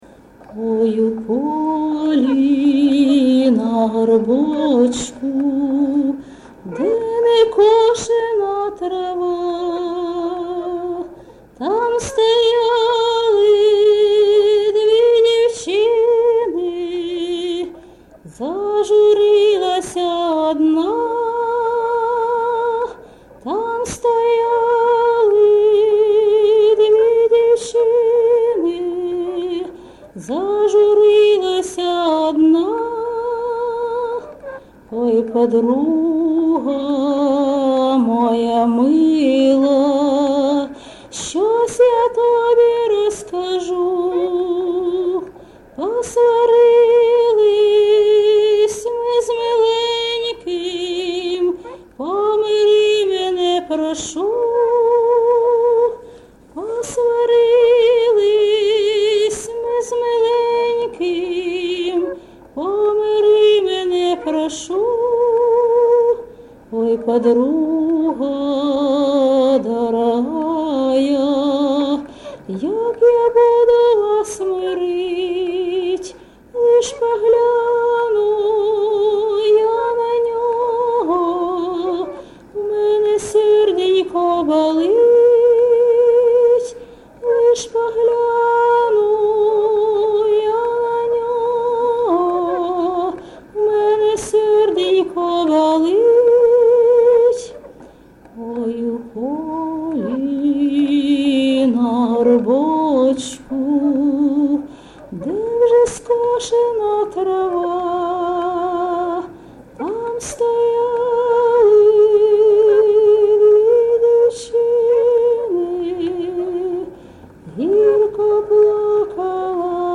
МотивКохання, Нещасливе кохання, Журба, туга